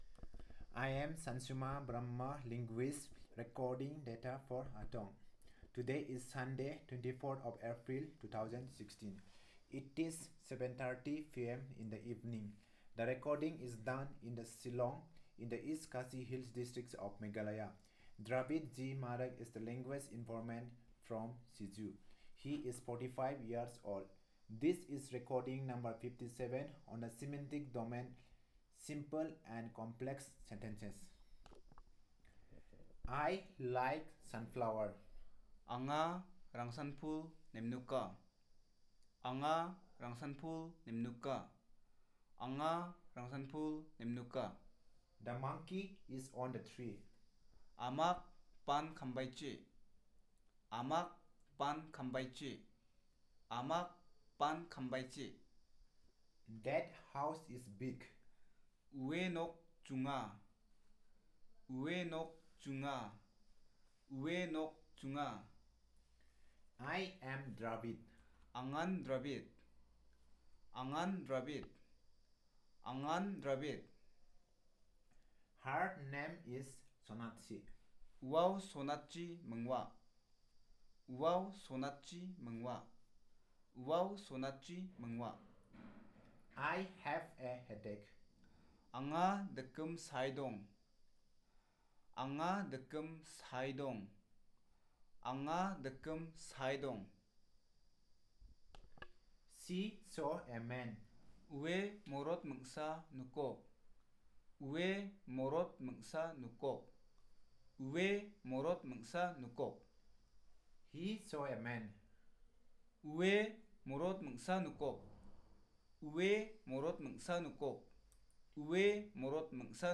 Elicitation of sentences about simple and complex sentences